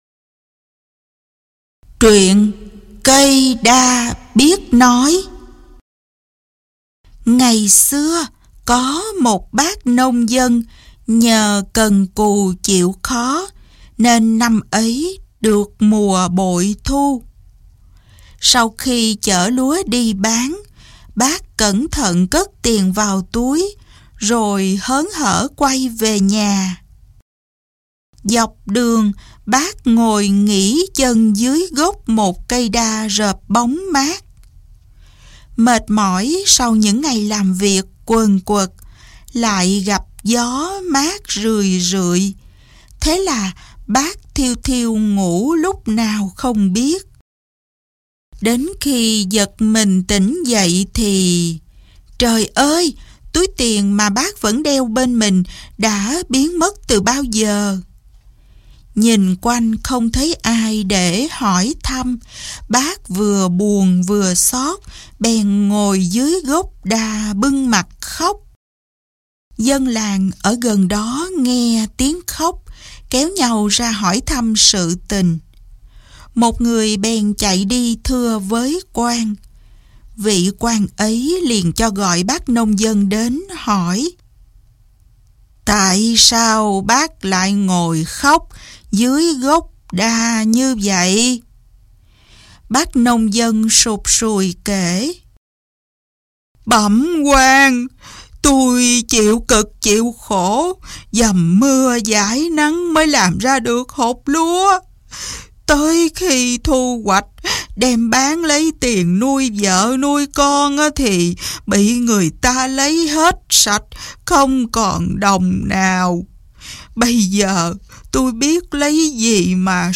Sách nói | Cây đa biết nói